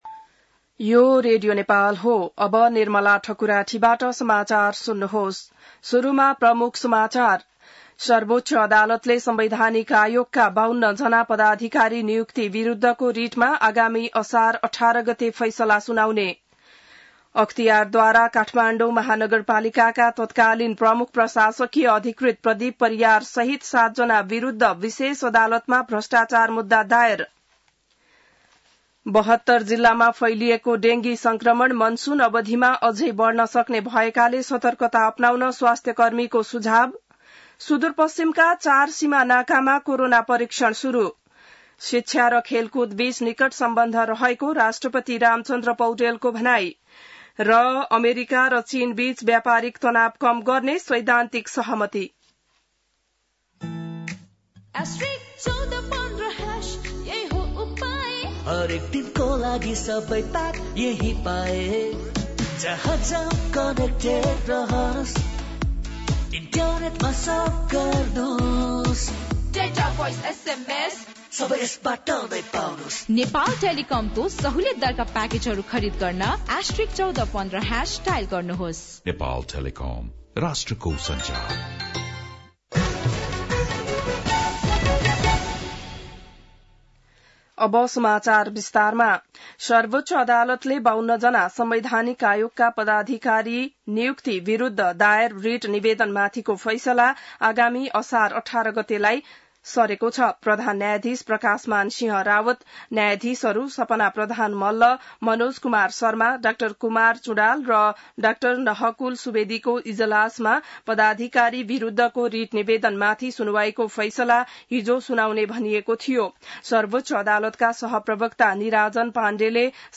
बिहान ७ बजेको नेपाली समाचार : २९ जेठ , २०८२